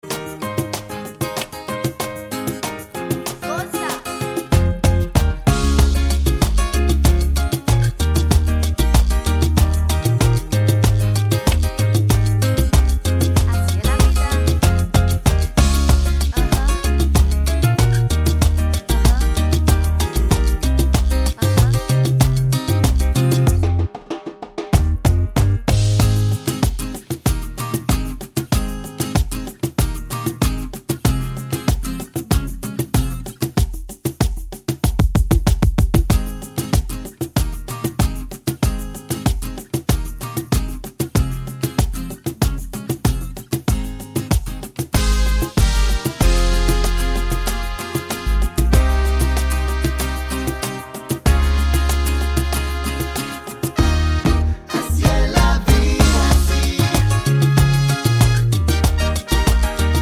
no Backing Vocals Pop (2020s) 3:28 Buy £1.50